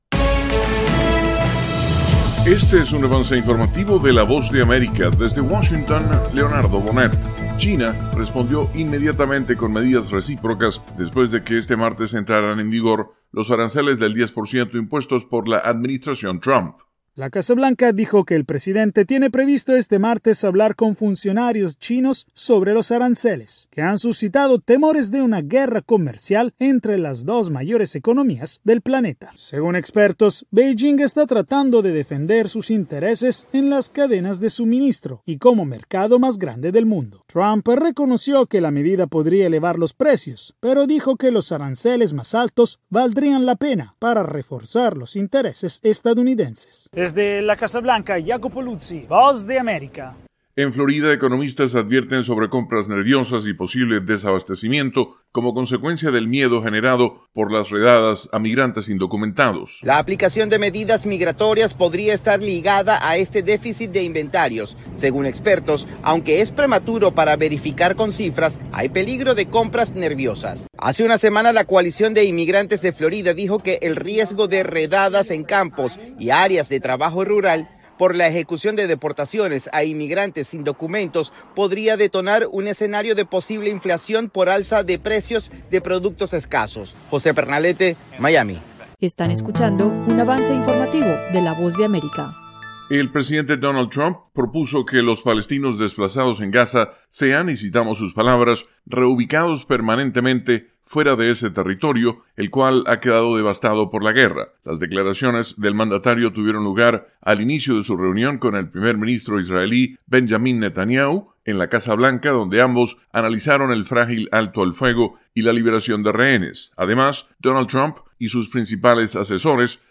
Avance Informativo
El siguiente es un avance informativo presentado por la Voz de América, desde Washington